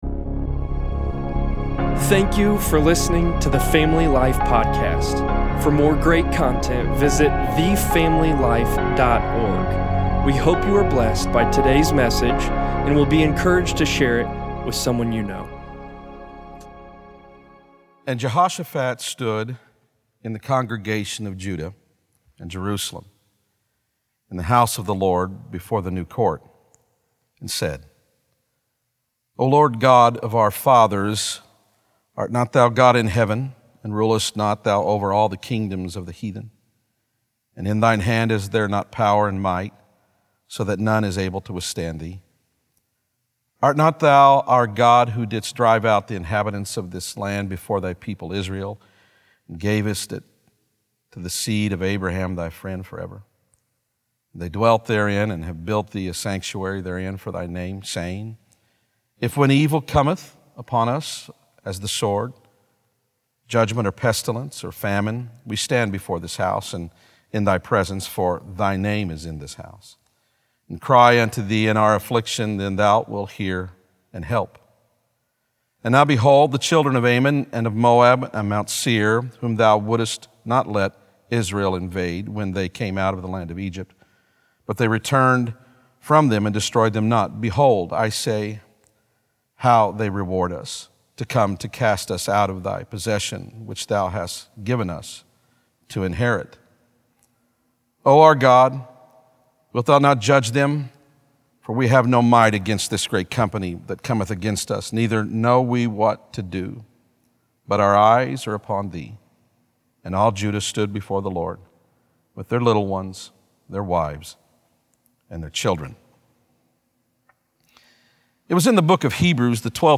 3.29.20_sermon_p.mp3